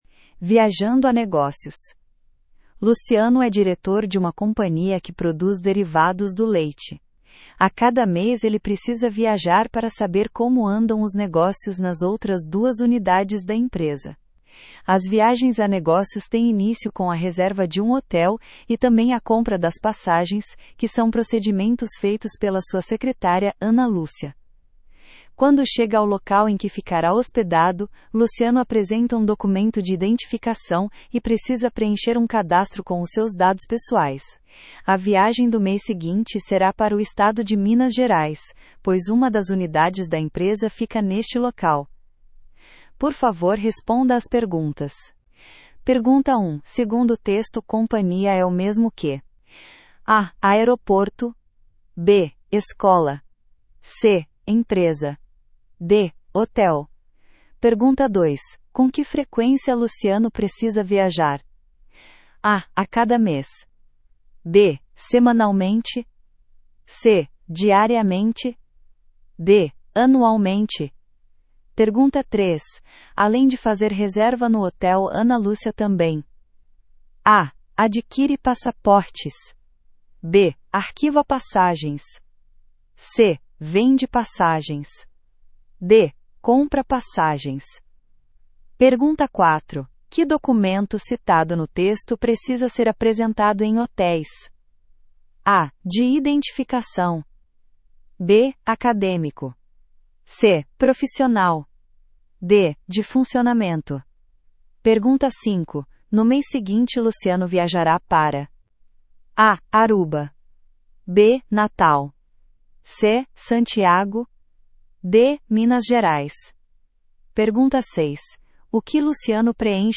Brasile